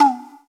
slapSignal.wav